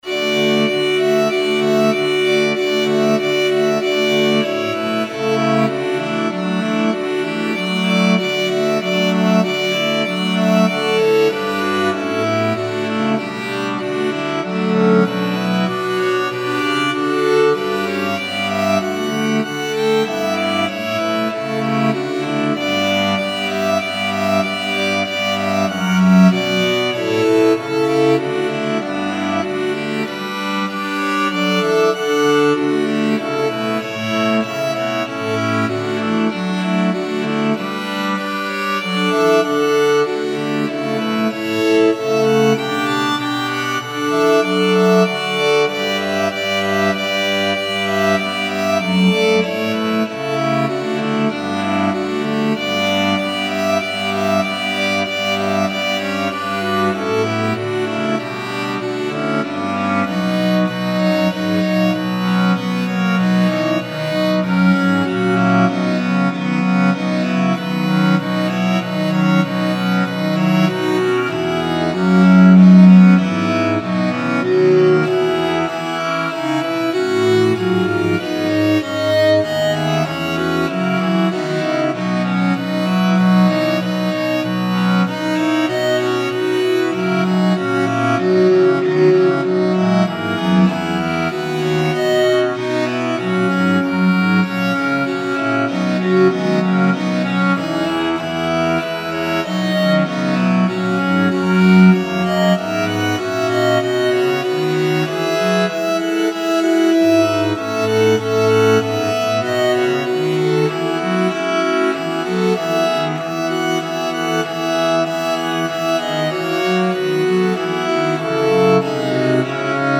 Oktoberreigen für vier Gamben